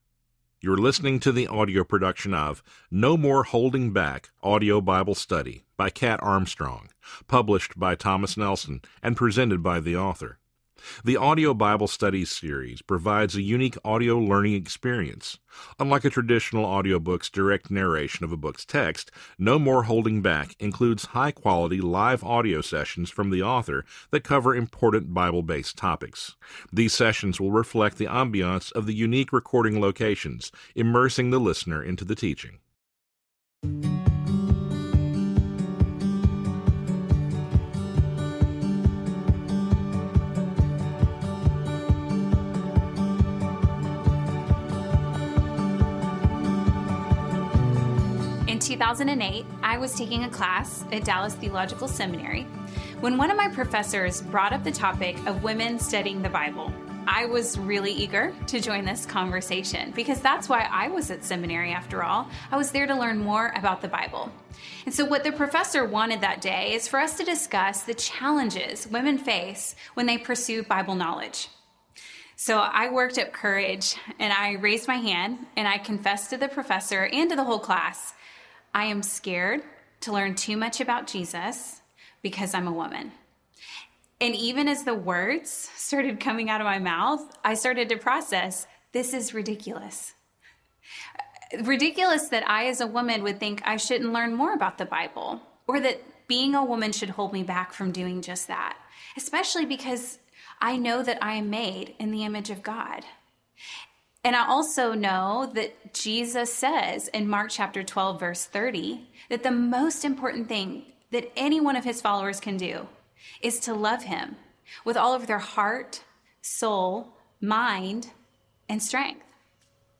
Narrator
6.33 Hrs. – Unabridged